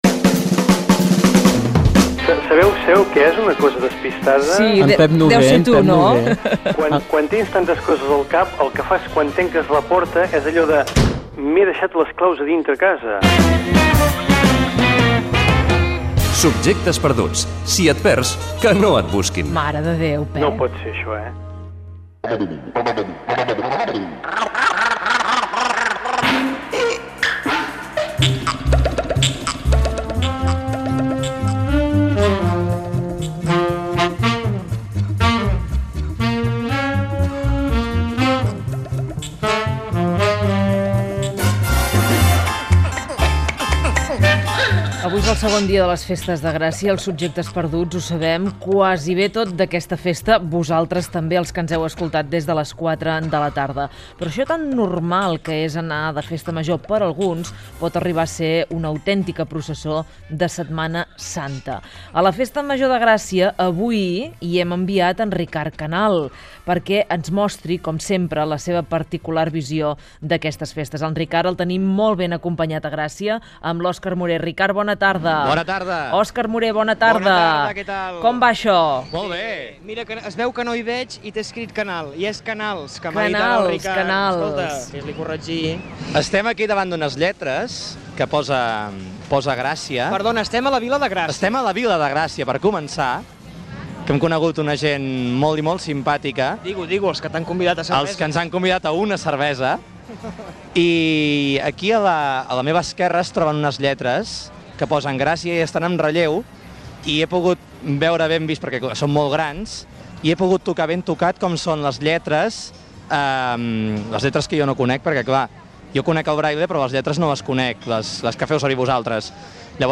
Identificació del programa, connexió amb la unitat mòbil que està a la festa major de Gràcia.
Entreteniment
Fragment extret de l'arxiu sonor de COM Ràdio